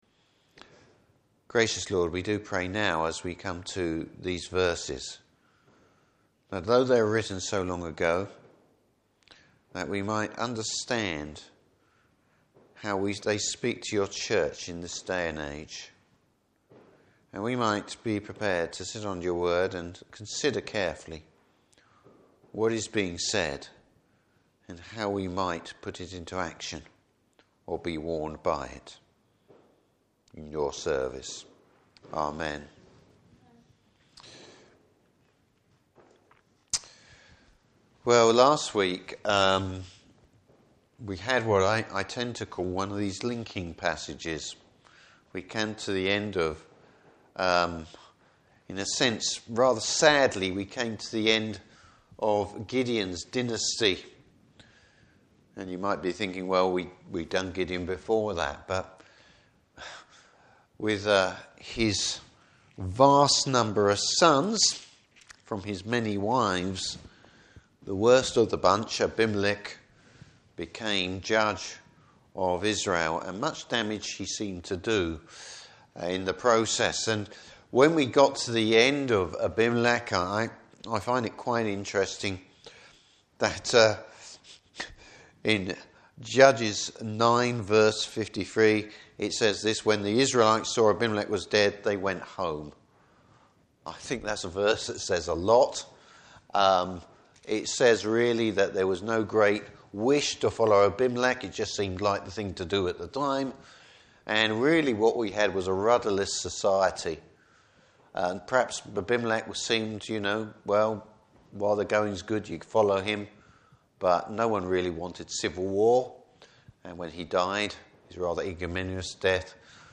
Service Type: Evening Service Bible Text: Judges 11:1-28.